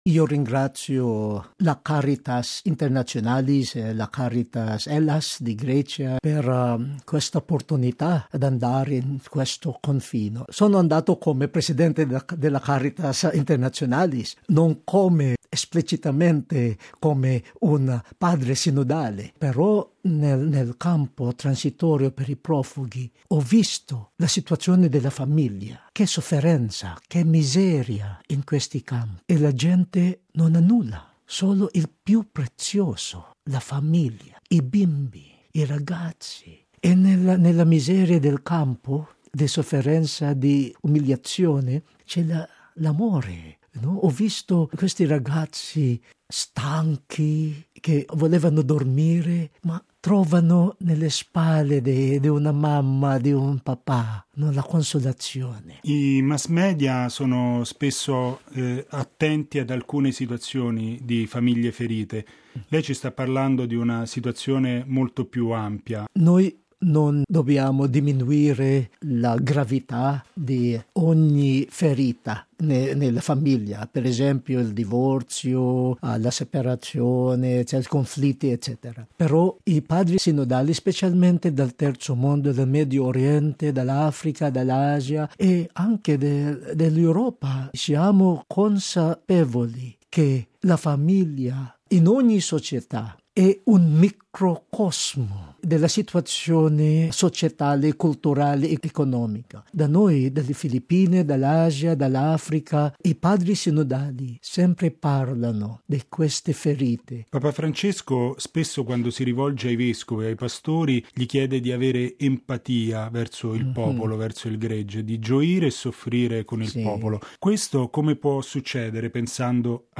Il Sinodo sulla famiglia, il Giubileo della Misericordia, la situazione dei profughi che cercano un futuro migliore in Europa. Sono i temi forti di un’intervista che il card. Luis Antonio Tagle, presidente di Caritas Internationalis e presidente delegato del Sinodo, ha rilasciato negli studi della Radio Vaticana.